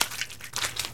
barnacle_tongue2.ogg